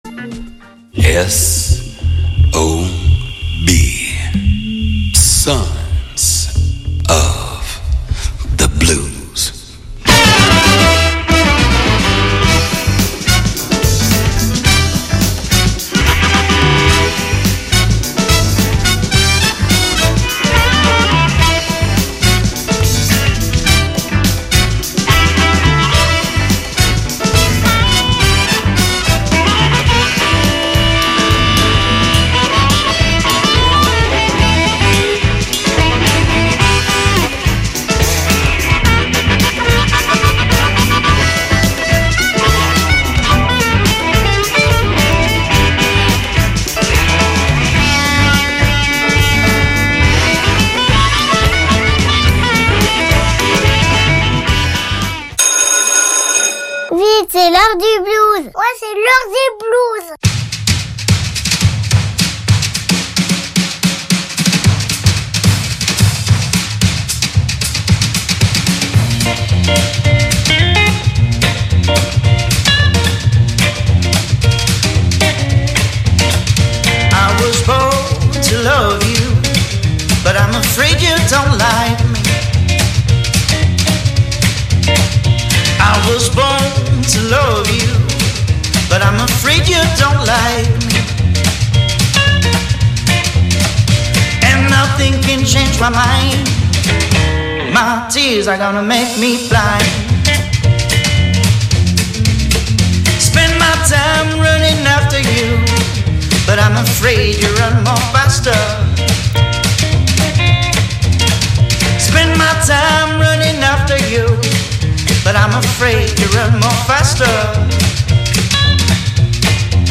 Puisque nous sommes tous des fils et filles du blues, il est bon de se retrouver chaque jeudi à 21H pour 1H de blues d’hier, d’aujourd’hui ou de demain.I